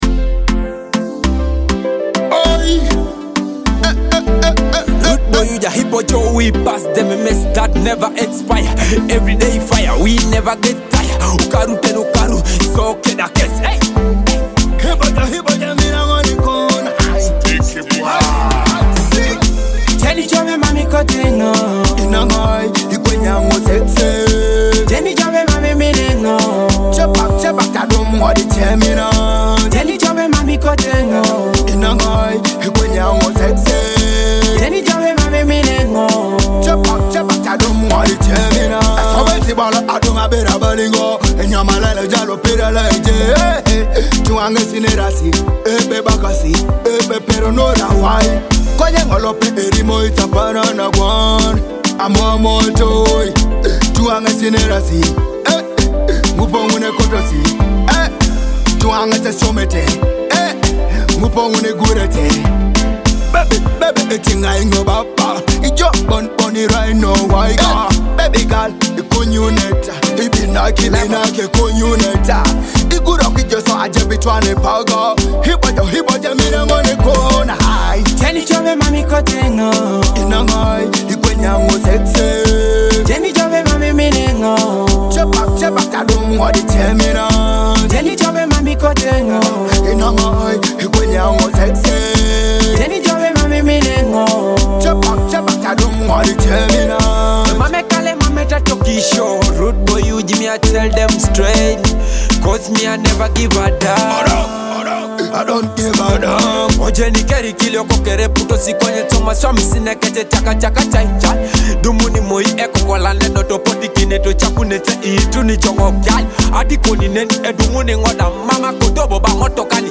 a dynamic blend of Afrobeat and Teso rhythms.